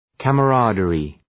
Προφορά
{,kæmə’rædərı}